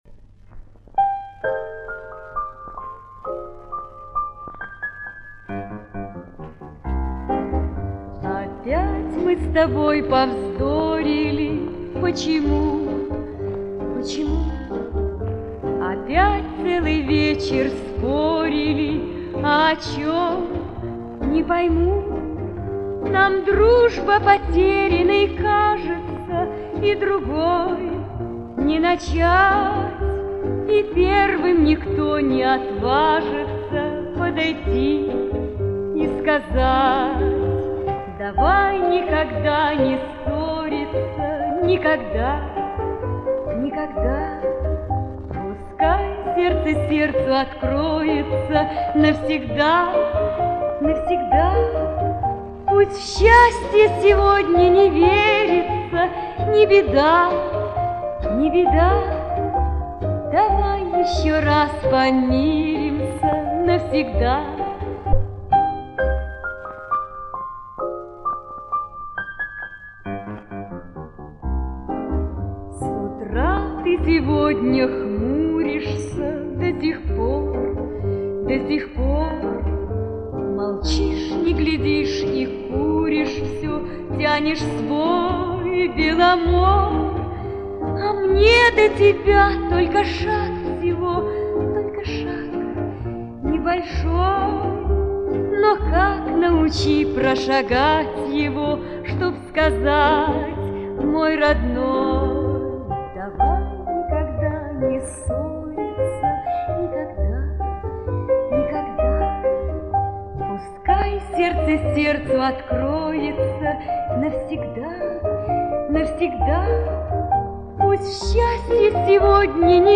оригинал в тональности До минор